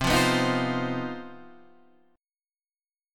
CmM7bb5 chord {8 8 9 8 6 7} chord